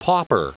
Prononciation du mot pauper en anglais (fichier audio)
Prononciation du mot : pauper